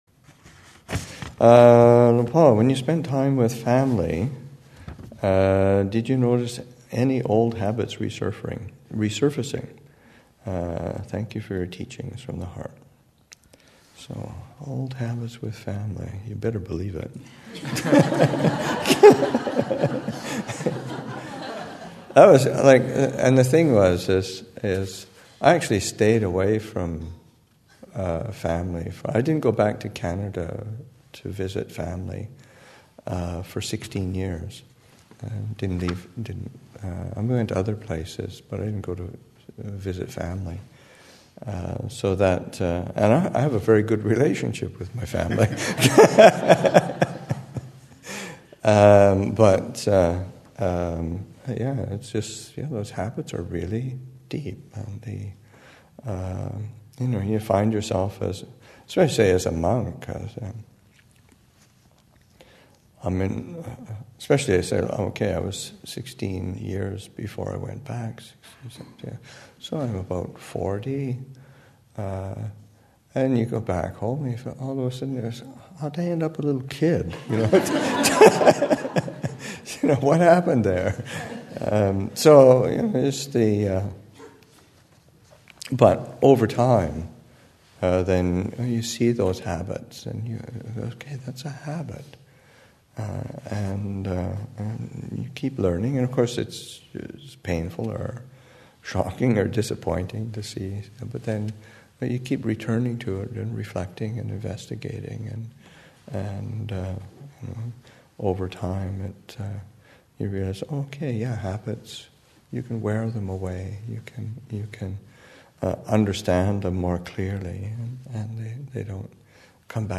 2015 Thanksgiving Monastic Retreat, Session 8 – Nov. 28, 2015